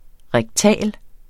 Udtale [ ʁεgˈtæˀl ]